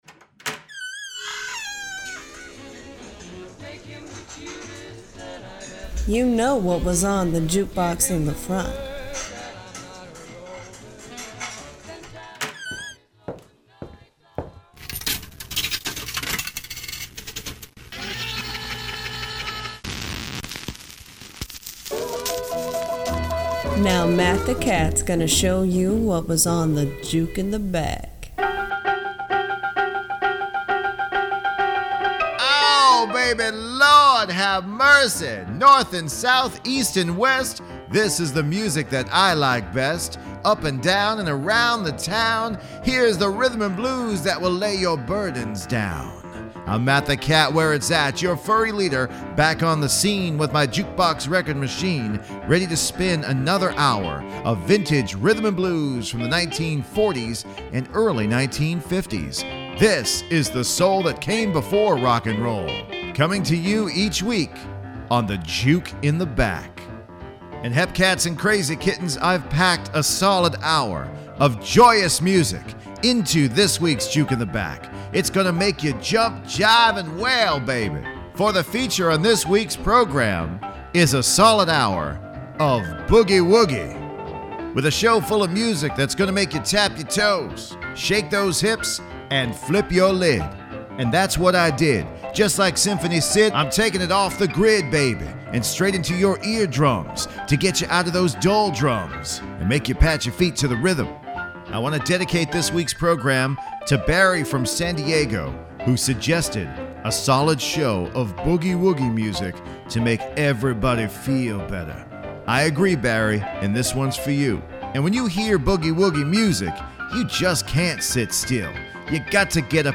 guitar and saxophone boogies